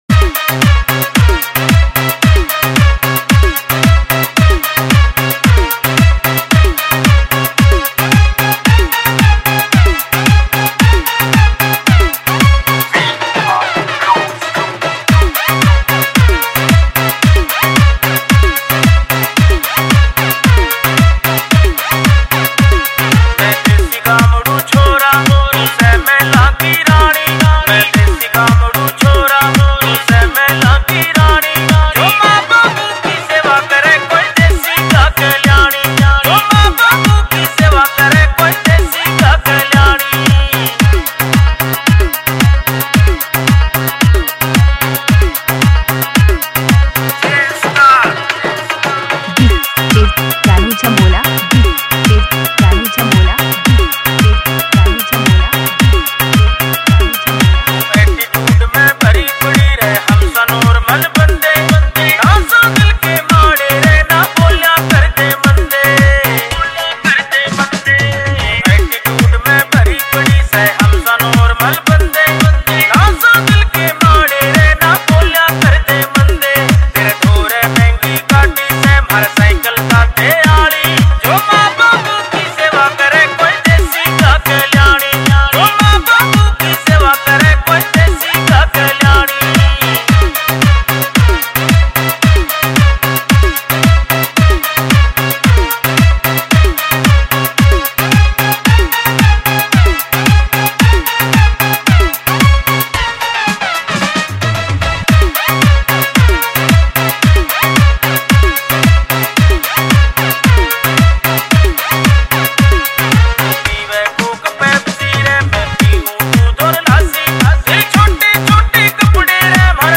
[ Haryanvi Songs ]